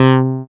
95 CLAV   -L.wav